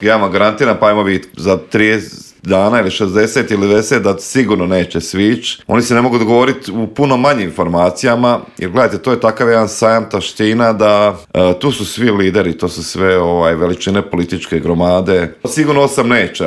Samo su neka od pitanja na koje smo u Intervjuu Media servisa odgovore potražili od saborskog zastupnika iz Domovinskog pokreta Marija Radića koji je najavio veliki politički skup stranke 23. ožujka u Zagrebu.